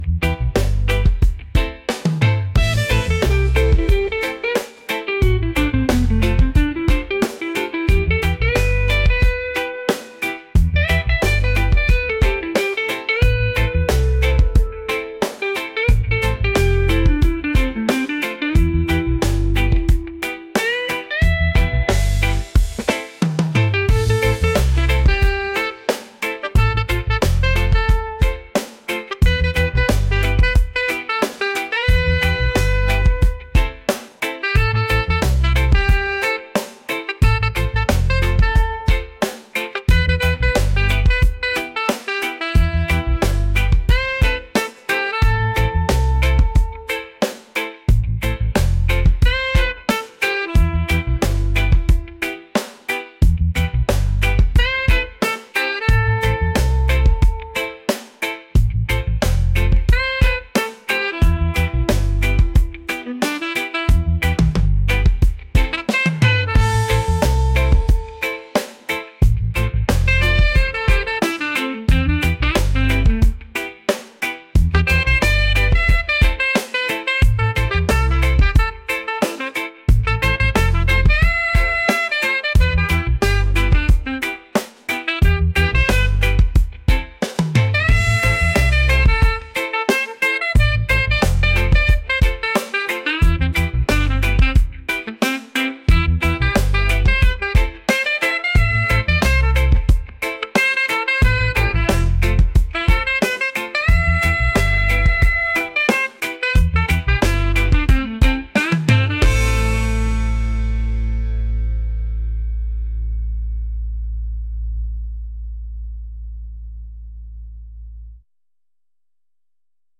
groovy | soulful | reggae